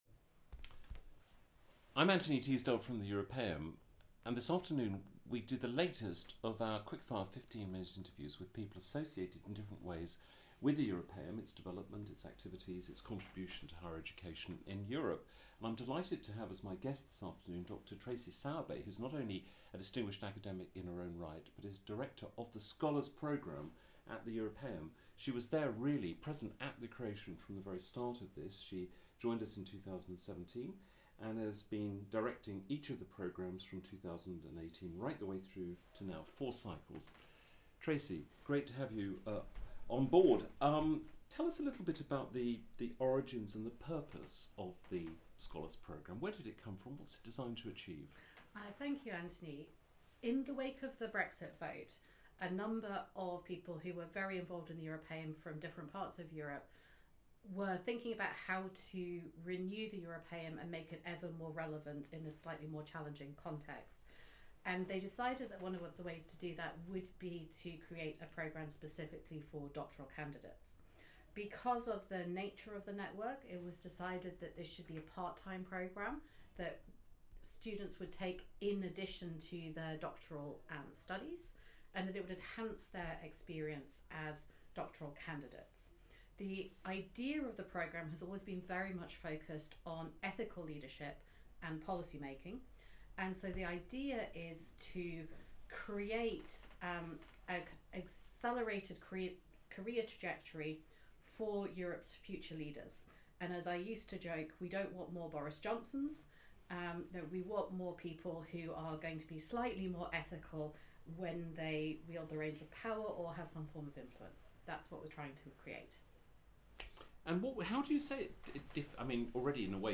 Europaeum Interviews